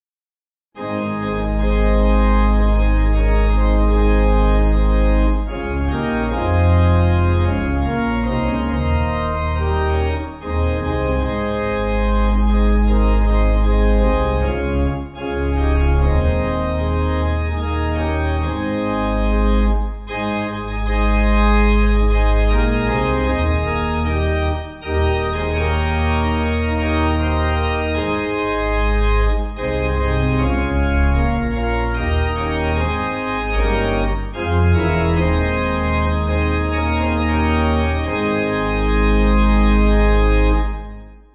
8.7.8.7.D